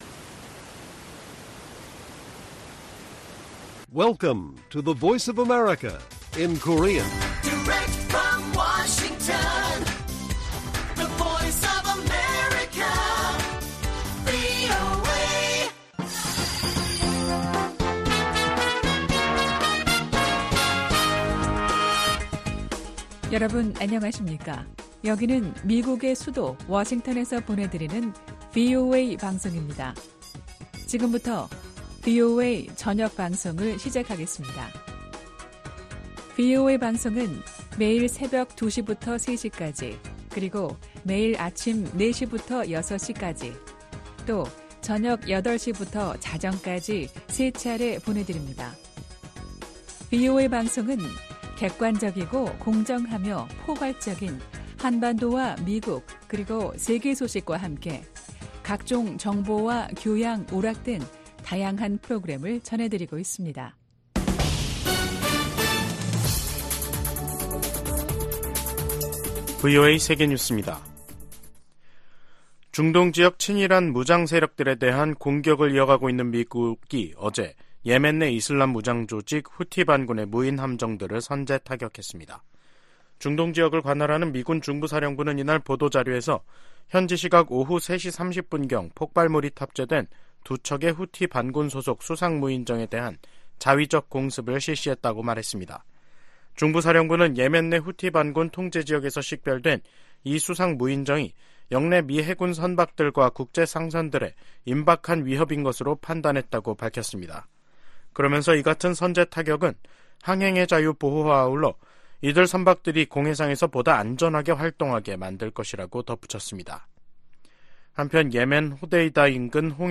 VOA 한국어 간판 뉴스 프로그램 '뉴스 투데이', 2024년 2월 6일 1부 방송입니다. 북한 핵 정책을 비판한 윤석열 한국 대통령에 대해 러시아 외무부 대변인이 비판 논평을 내면서 갈등이 악화될 조짐을 보이고 있습니다. 미국 정부는 북한의 미사일 경보 정보를 한국· 일본과 계속 공유할 것이라고 밝혔습니다. 오는 11월 도널드 트럼프 전 대통령이 당선되면 임기 초 북한과 협상할 가능성이 있다고 존 볼튼 전 국가안보보좌관이 VOA 인터뷰에서 전망했습니다.